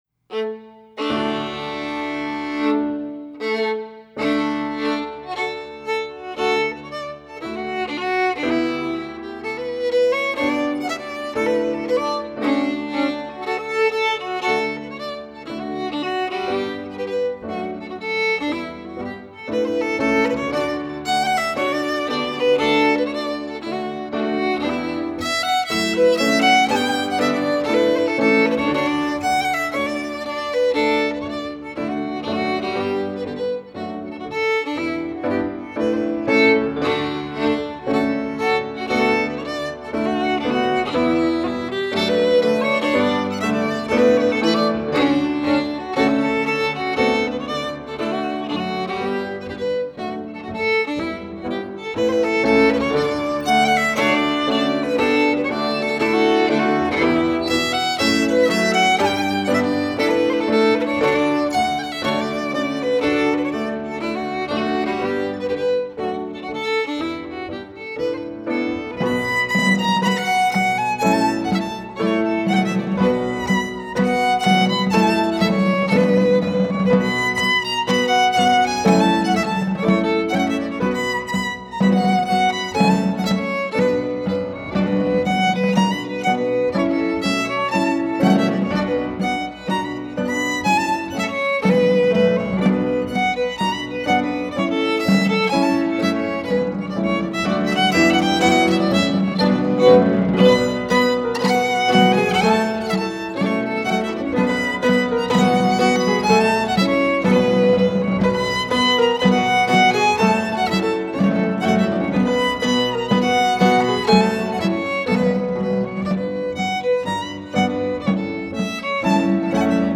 Scottish and Québécois fiddling.
piano
percussion